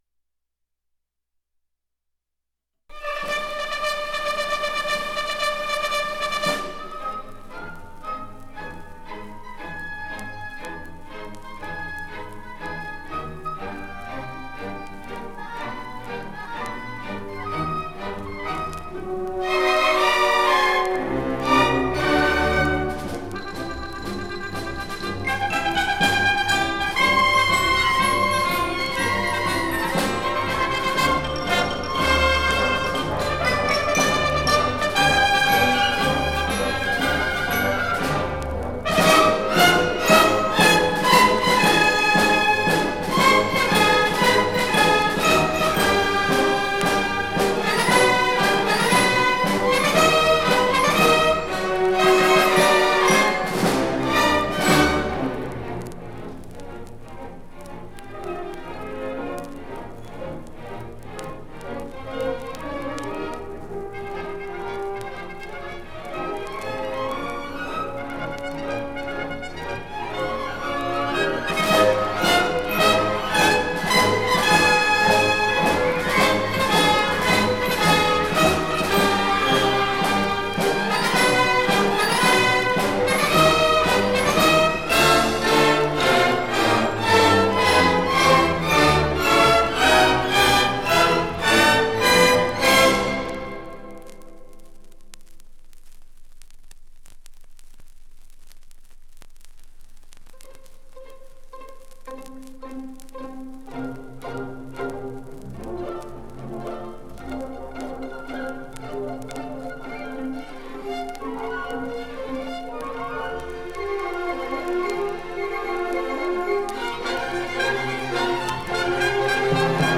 3e250f8d19e155e8bd57bc94f897159b1ff65de4.mp3 Title 1971 Music in May orchestra performance recording Description An audio recording of the 1971 Music in May orchestra performance at Pacific University.
It brings outstanding high school music students together on the university campus for several days of lessons and events, culminating in the final concert that this recording preserves.